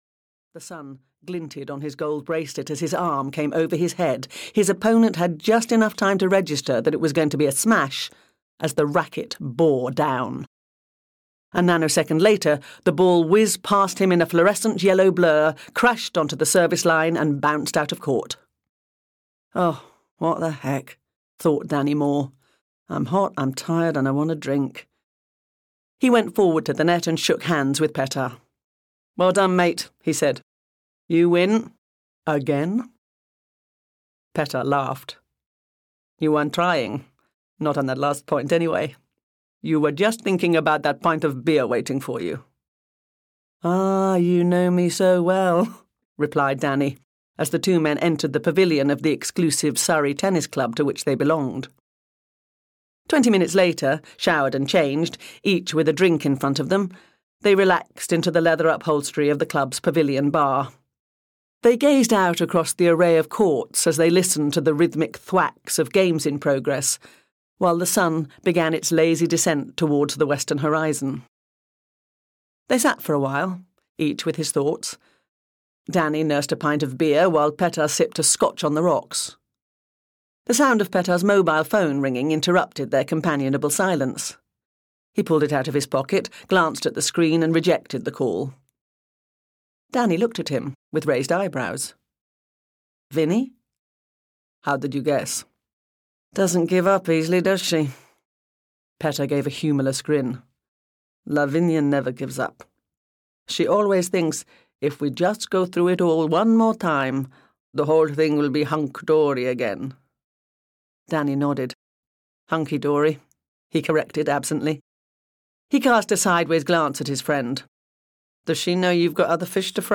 Game, Set and Murder (EN) audiokniha
Ukázka z knihy